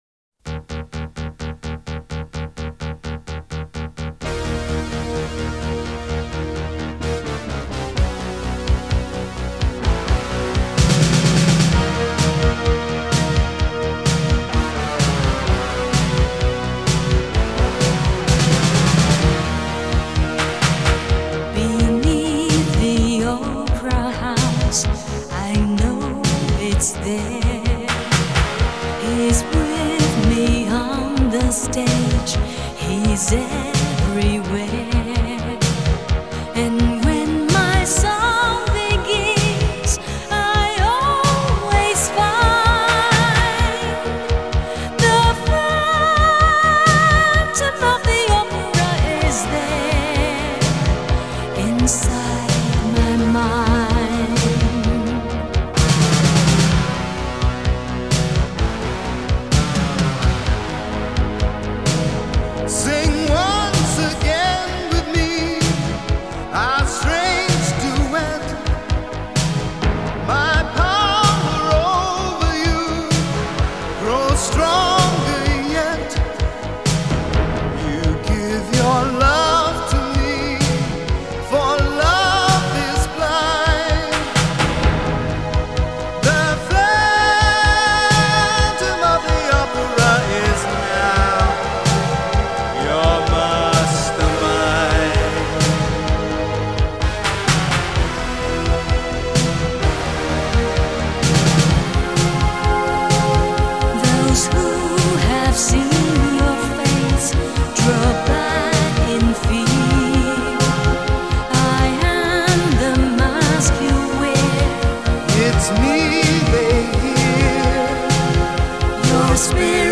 Жанр: Chinese pop / Pop